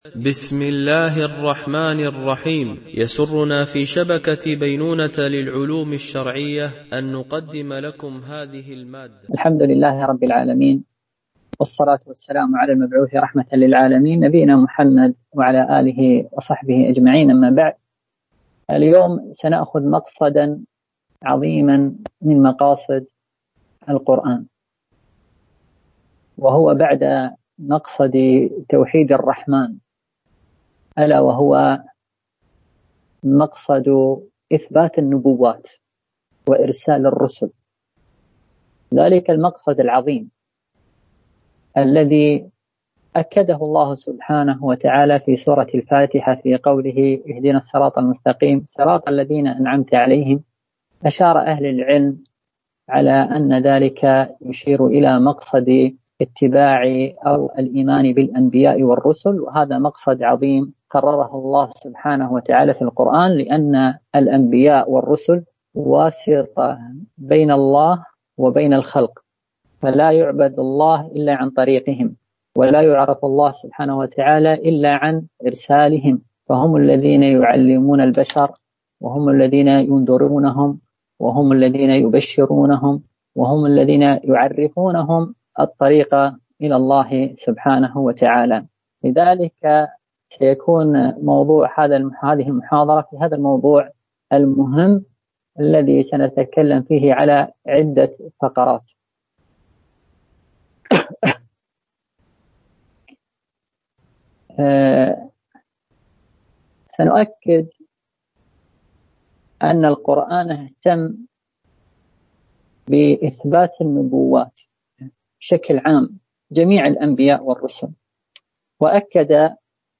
سلسلة محاضرات في تفسير القرآن الكريم - المحاضرة 7 ( من مقاصد القرآن العظام إثبات النبوات)